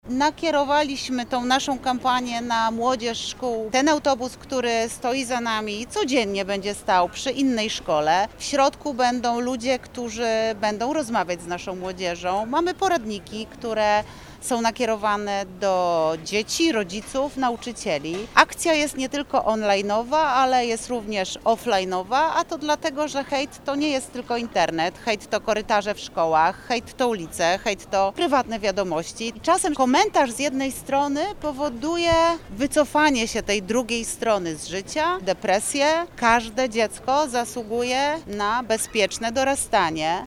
– Robimy głośny hałas we Wrocławiu i mówimy „stop” hejtowi. Kampania ma mieć realny wpływ i skierowana jest do uczniów, jak i nauczycieli, rodziców, opiekunów – zaznacza Renata Granowska, wiceprezydent Wrocławia.